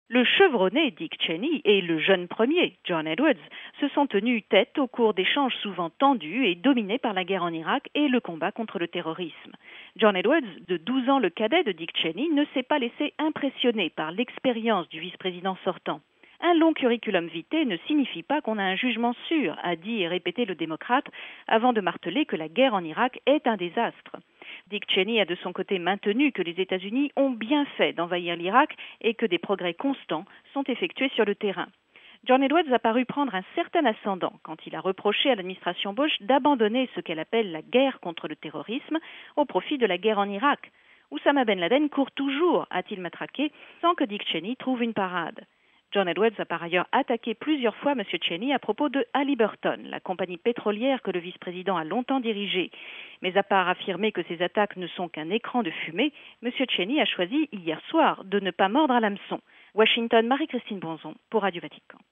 Correspondance à Washington